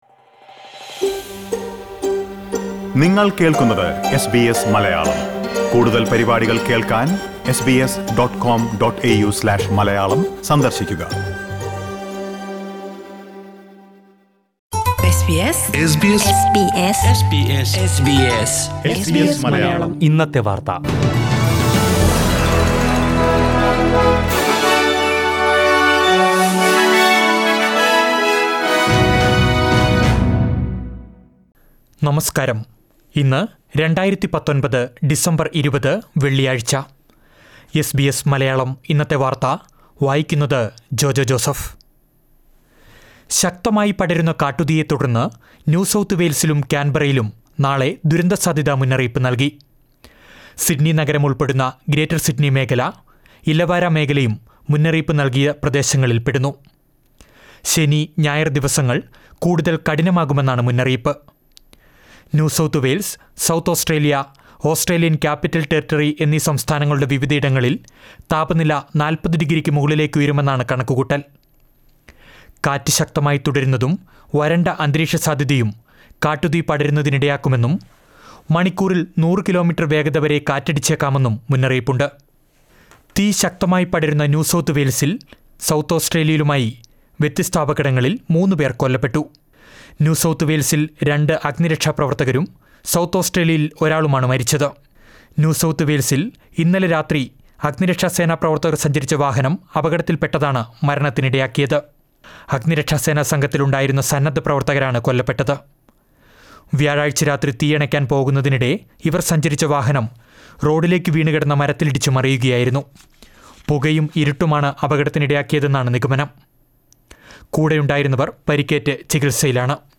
2019 ഡിസംബര്‍ 20ലെ ഓസ്ട്രേലിയയിലെ ഏറ്റവും പ്രധാന വാര്‍ത്തകള്‍ കേള്‍ക്കാം